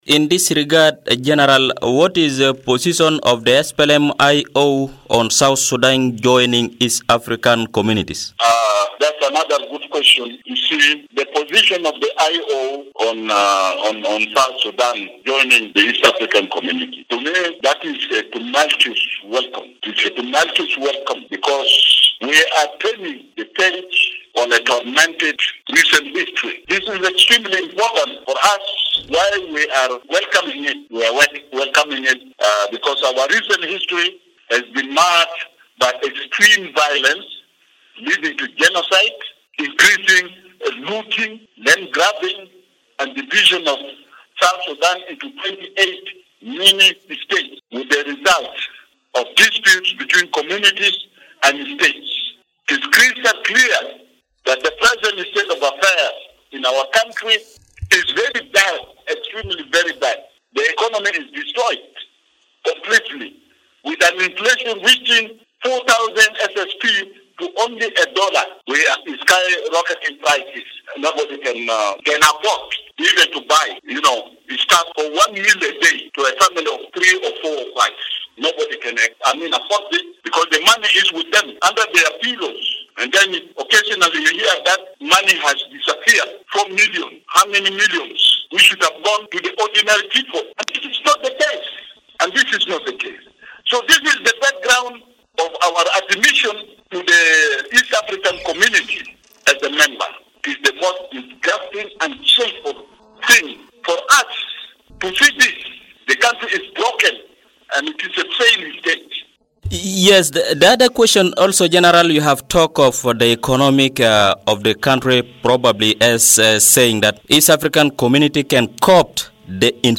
The SPLM/A -IO Deputy Leader was talking to Radio Bakhita in a phone interview today.